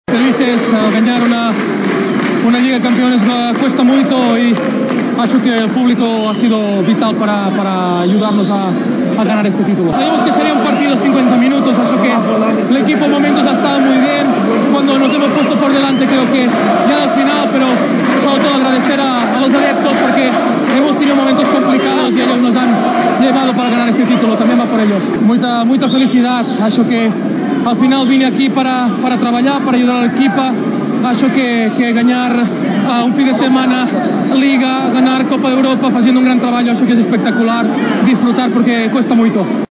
En declaracions a una televisió de Portugal, explicava que costa molt de treball poder aconseguir aquesta copa tant important.